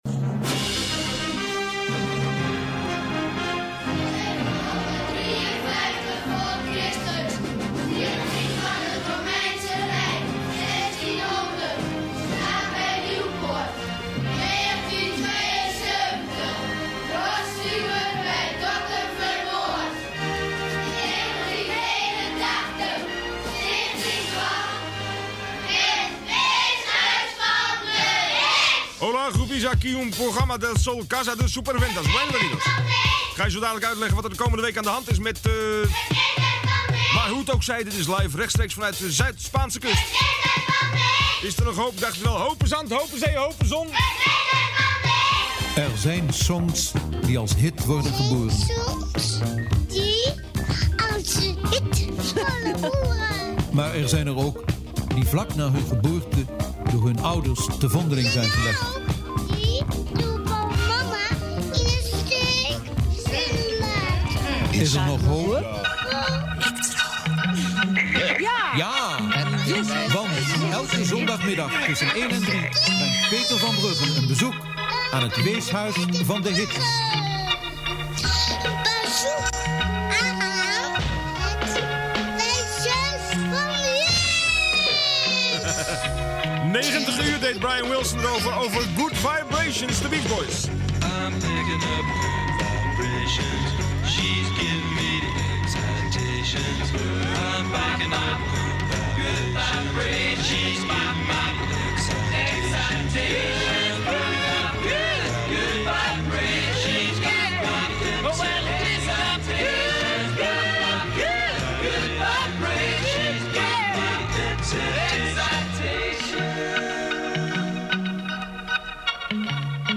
Weeshuis van de Hits 1986 (stranduitzending Zuid-Spanje)
1986-Stranduitzending-Zuid-Spanje.mp3